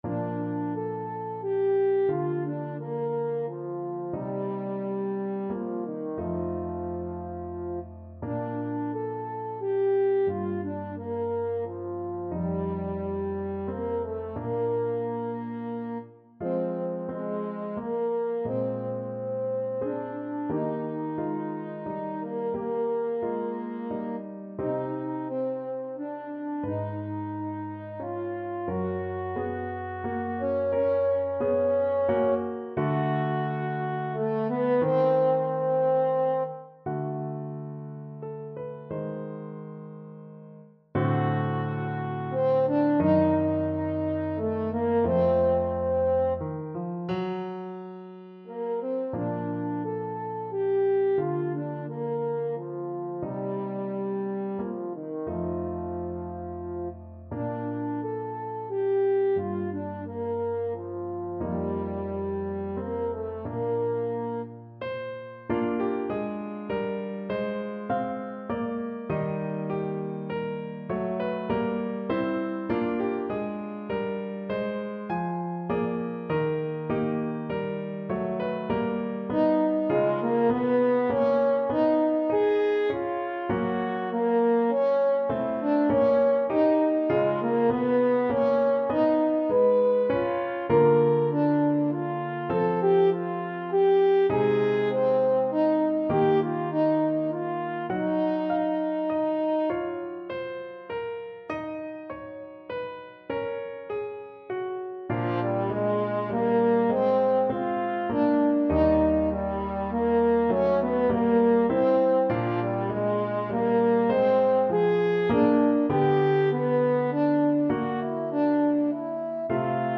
Classical Boëllmann, Léon Prière à Notre-Dame, Suite Gothique No.3 French Horn version
French Horn
Bb major (Sounding Pitch) F major (French Horn in F) (View more Bb major Music for French Horn )
= 88 Trs lent
6/4 (View more 6/4 Music)
Classical (View more Classical French Horn Music)